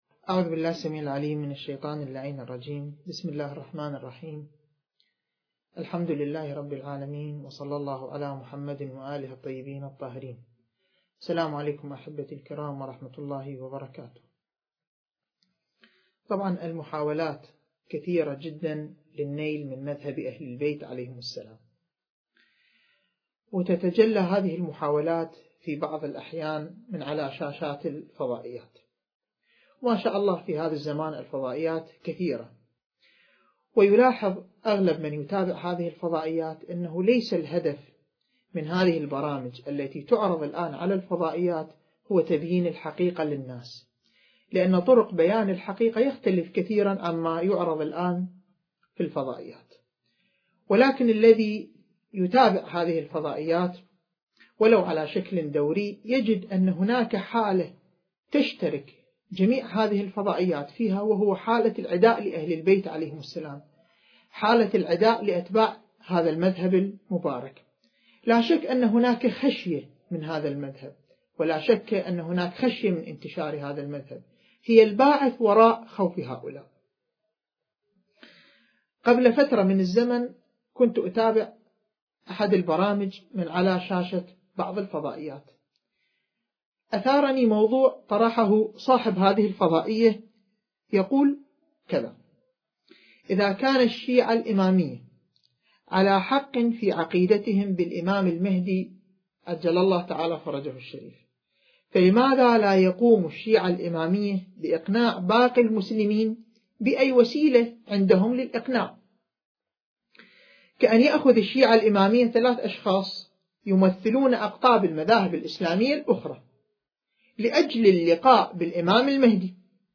المكان: استوديو مركز الدراسات التخصصية في الإمام المهدي (عجّل الله فرجه) / النجف الأشرف التاريخ: ٢٠١١